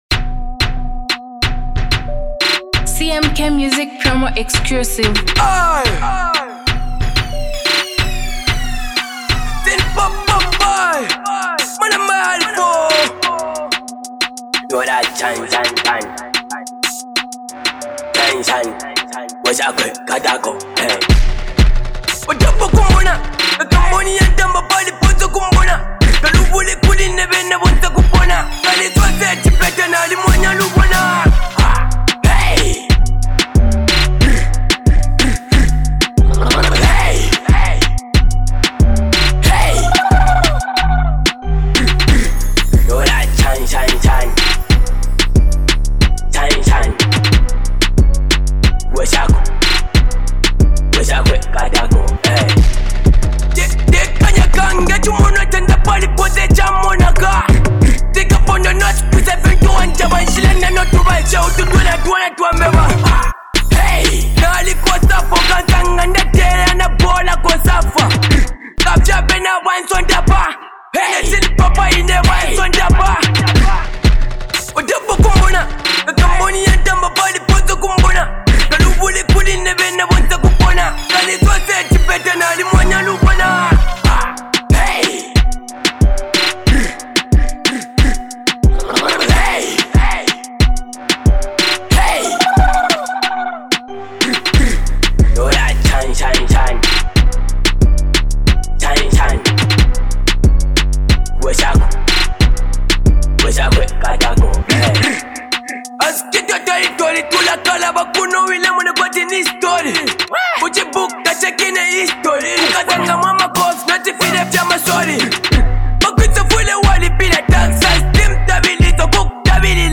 lyrical dexterity, and unmatched flow.